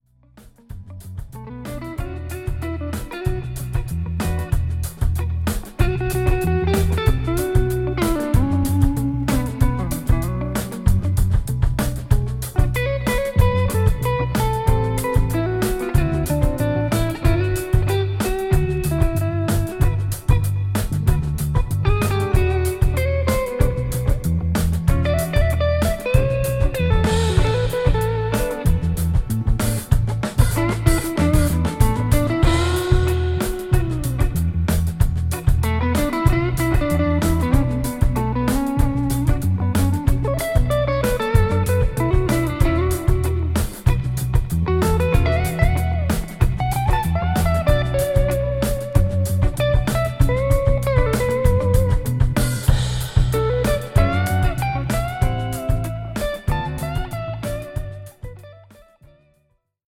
ソウルフルでコクのある極上グルーヴが揃った、インスト・レゲエの好盤です。